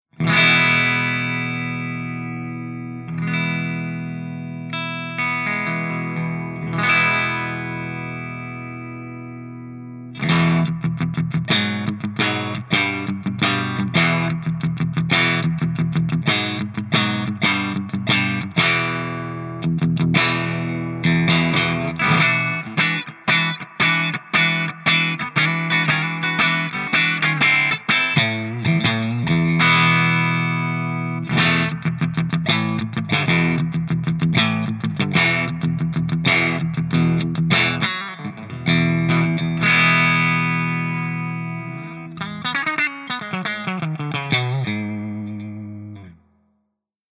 098_HIWATT_STANDARDCRUNCH_GB_P90.mp3